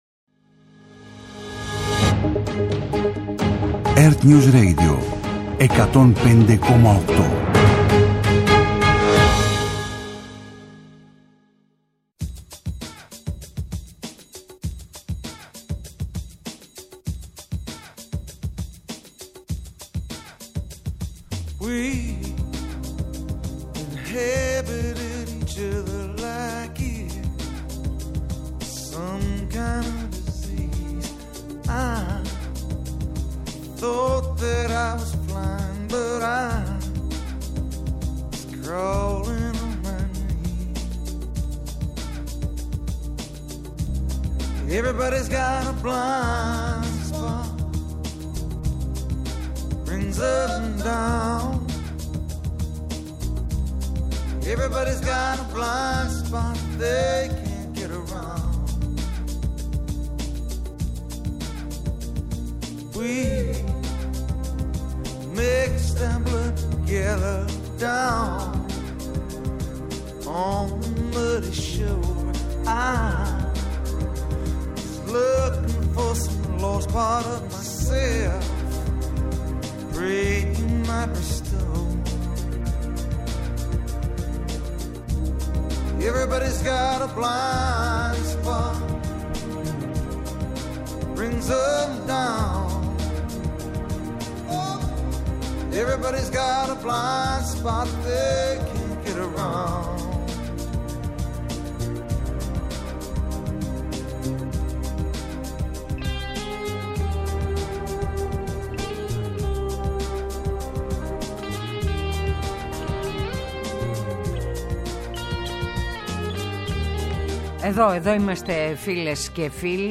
ΣΥΝΔΕΣΗ ΜΕ ΒΟΥΛΗ – Ομιλία Νίκου Ανδρουλάκη για την εξωτερική πολιτική της χώρας. Καλεσμένος ο Χρήστος Κέλλας, υφυπουργός Αγροτικής Ανάπτυξης.
ΣΥΝΔΕΣΗ ΜΕ ΒΟΥΛΗ – Ομιλία Σωκράτη Φάμελου για την εξωτερική πολιτική της χώρας.